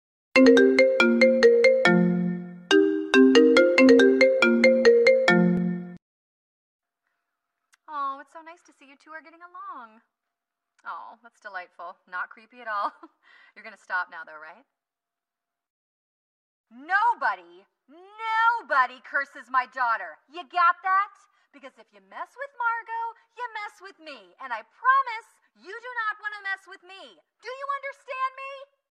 📌 Disclaimer: This is a fun fake call and not affiliated with any official character or franchise.